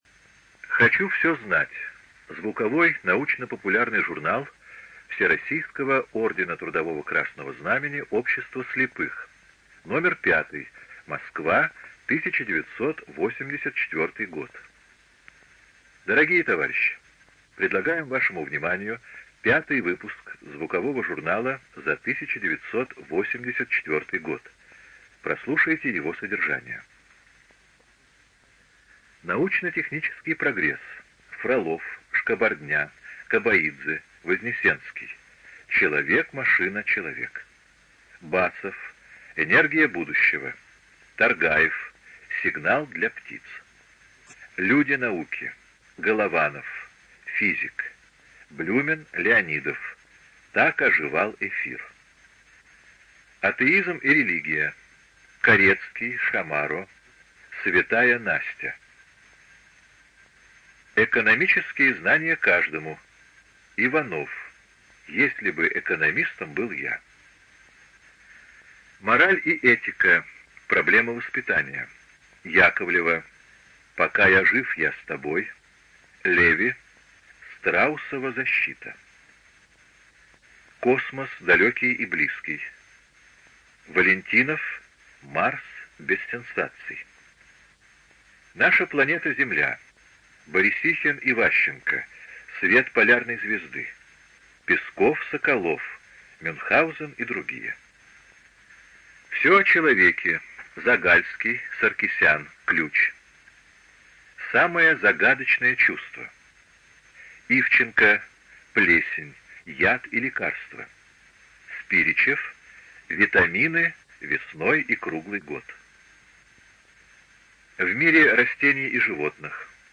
ЖанрНаучно-популярная литература
Студия звукозаписиЛогосвос